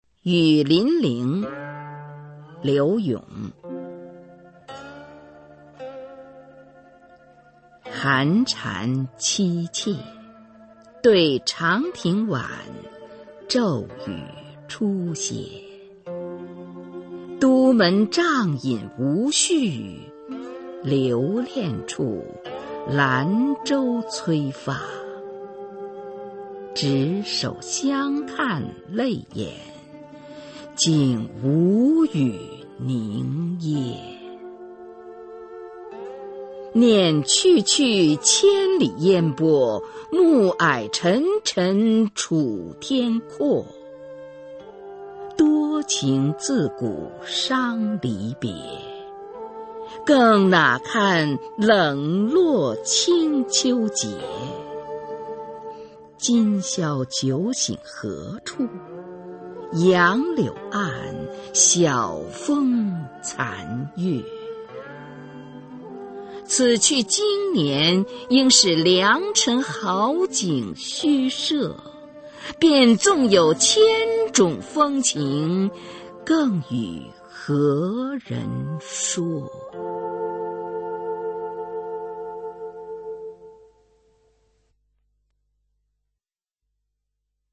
[宋代诗词朗诵]柳永-雨霖铃（女） 古诗词诵读